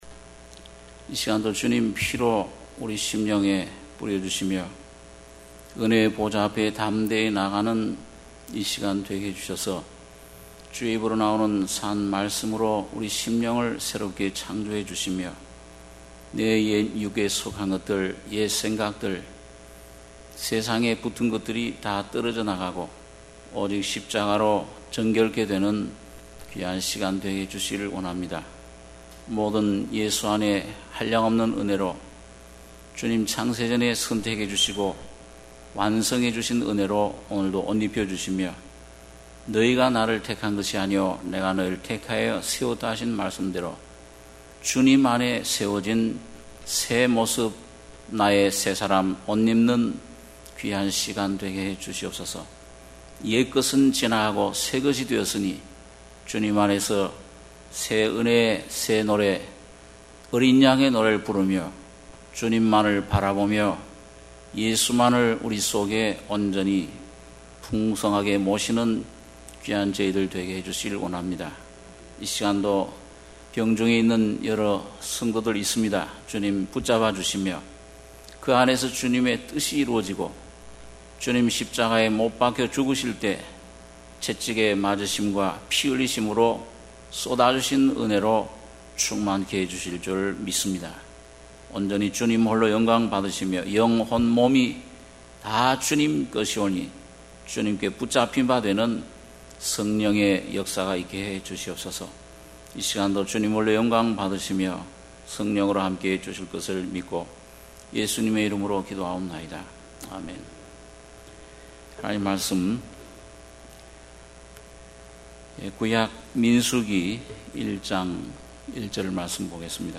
수요예배 - 민수기 1장 1-8절